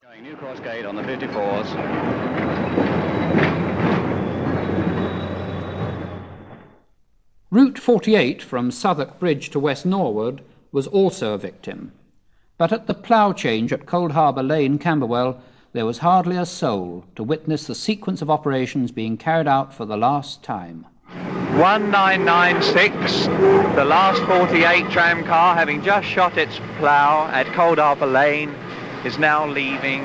Londons Last Trams Stage 6 Live recordings
Victoria 5 January 1952